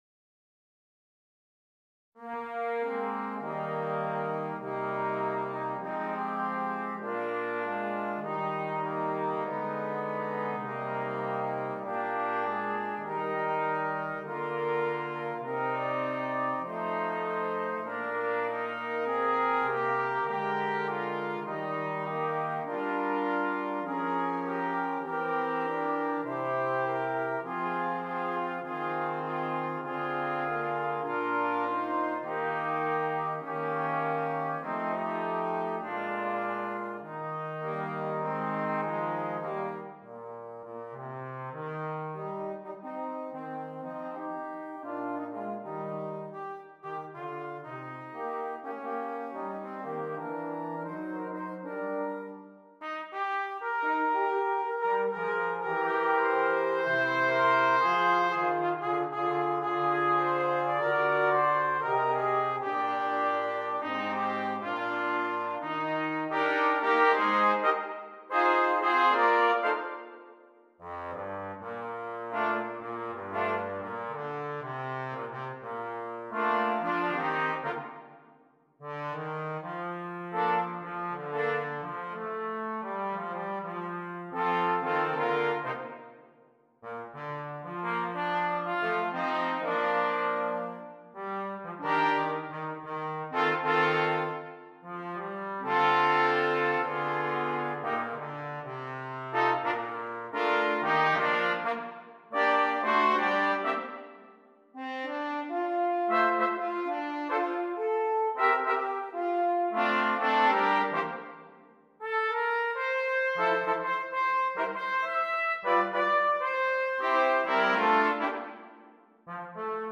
Brass Quartet
American Spiritual